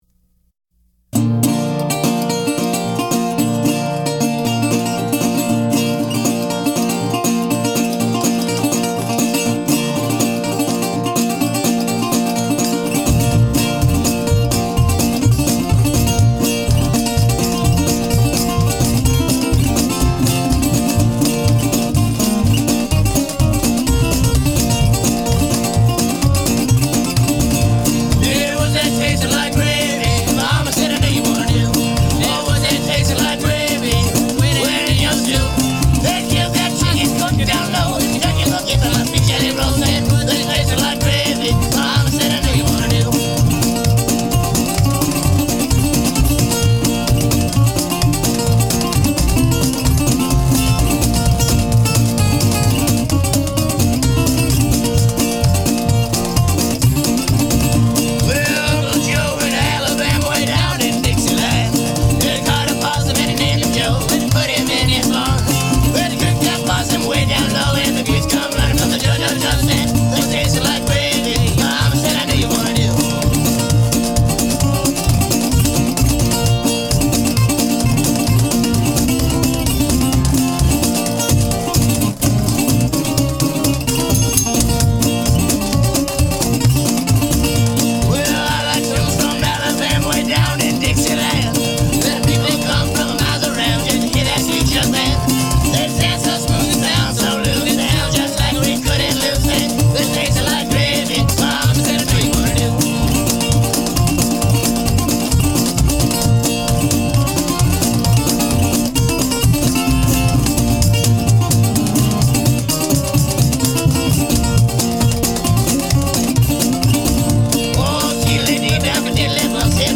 A ragtime gallimaufry from Eugene, Oregon U$A